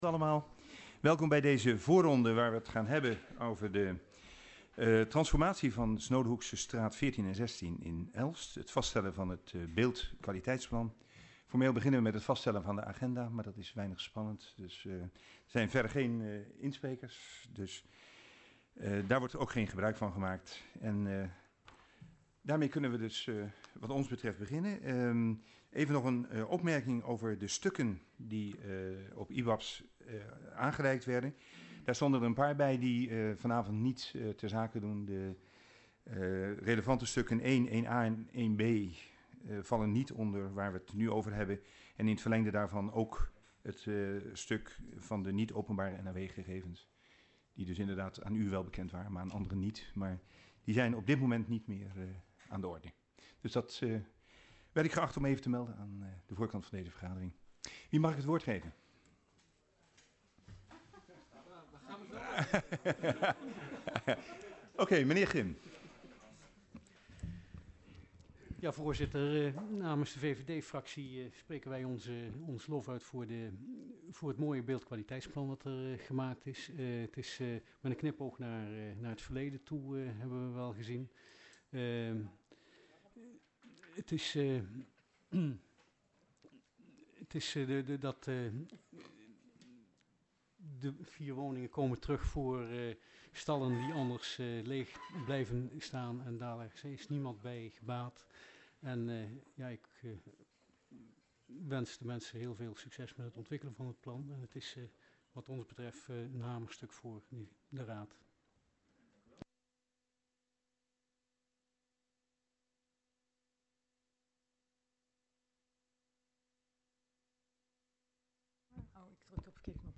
Locatie Hal, gemeentehuis Elst Voorzitter dhr.